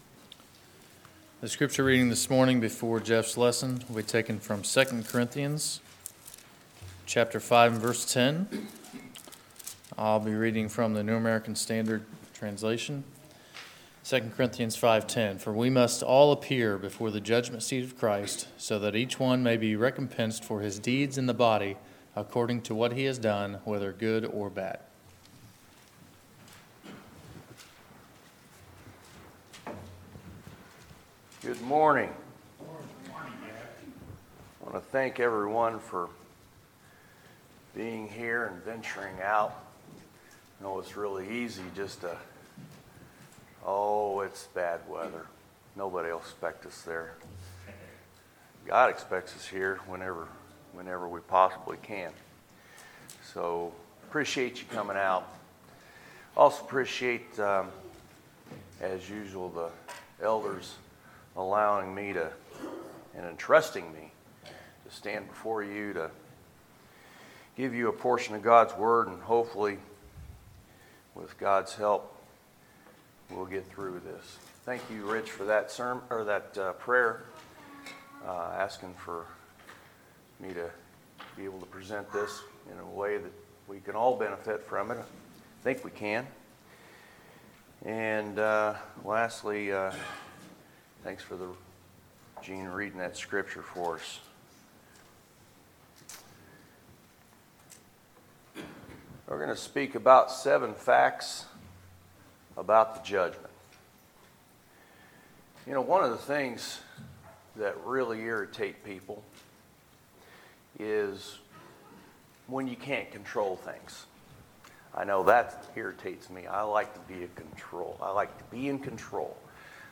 Sermons, January 13, 2019